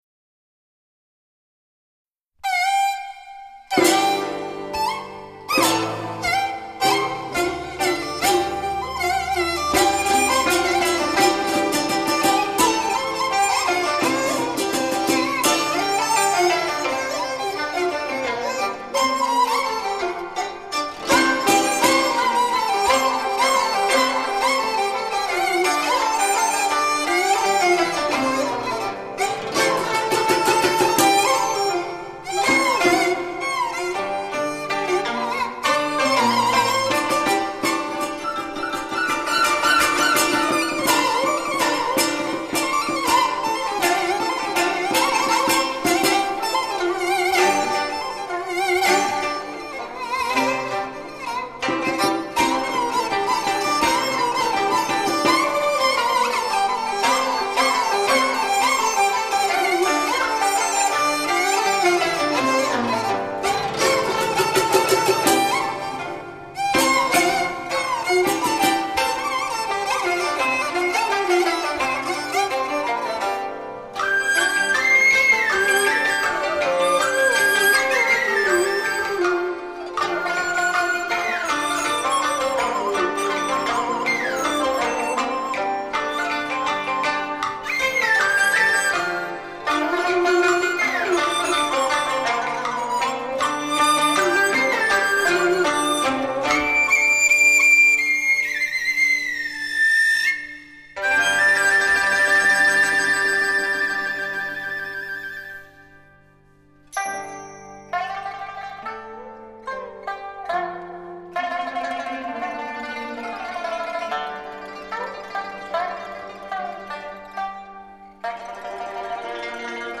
陕北民间音乐